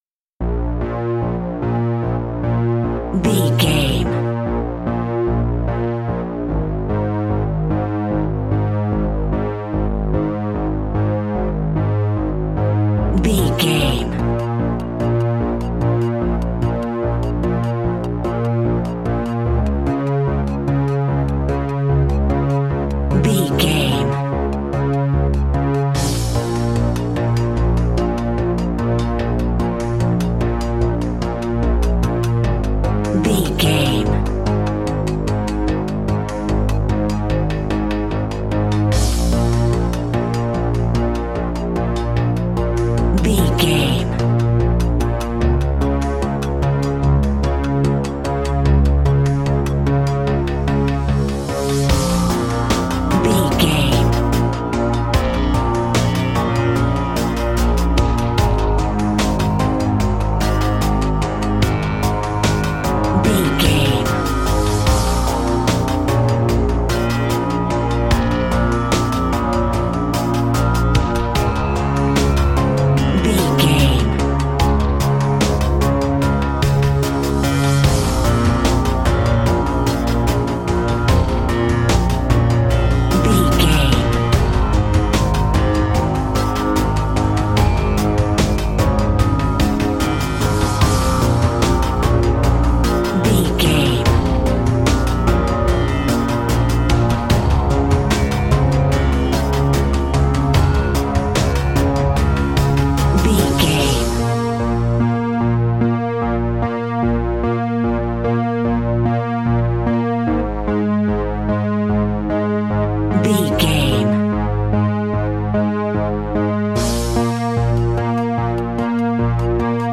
In-crescendo
Aeolian/Minor
B♭
ominous
dark
haunting
eerie
synthesiser
drums
mysterious
electronic music